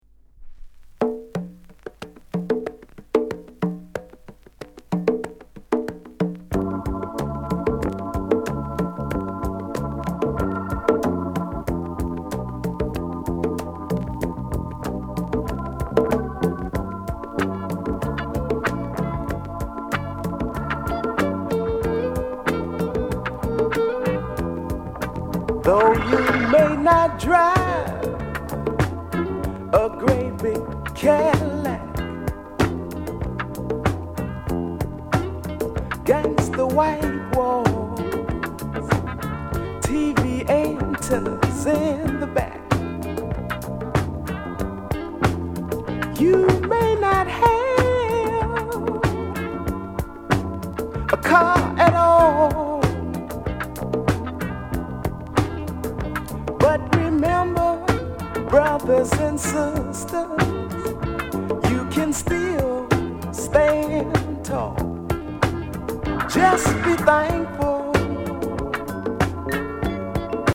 SOUL CLASSICS